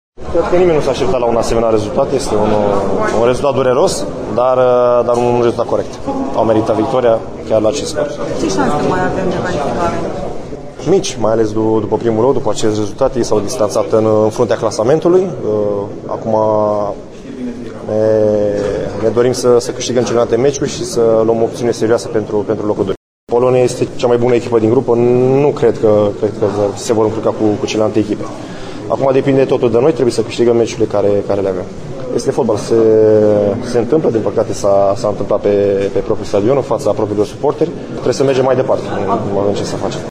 Portarul Ciprian Tătărușanu, care are parte de vină la golul secund, vorbește despre acest insucces.